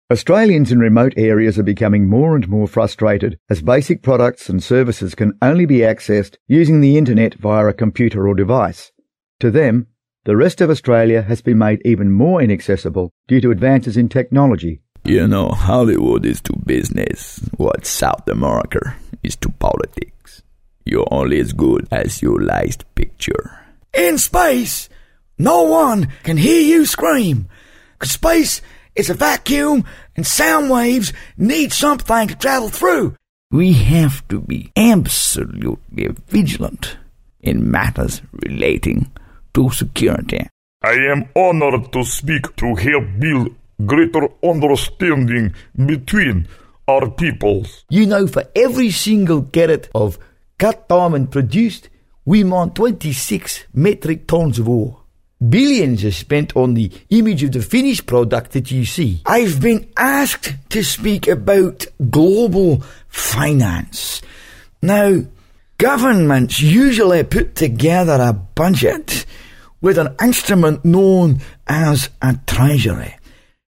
Male
My normal voice is conversational, an articulate Australian in instructional videos but as an impersonator, I specialize in character voices, accents and dialects, sounding like famous speaking and singing voices from Elvis to Sean Connery.
Natural Speak
Accents/Dialects 7 Examples
0521Accents_dialects_Australian_Italian_American_Posh_English_Russian_Sth_African_Scot.mp3